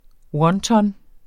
Udtale [ ˈwʌnˌtʌn ]